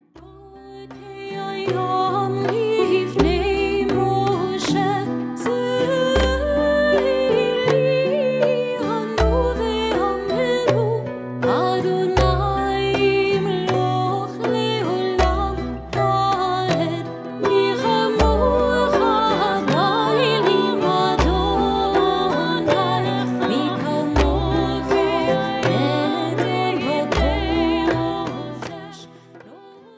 • Choral
CJM (Contemporary Jewish Music)